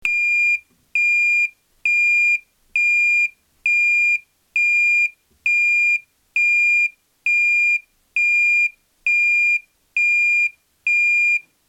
圧電ブザー ESZ-26B DC5V～28V
音量dB/m(A) › (連続/断続) 105/10cm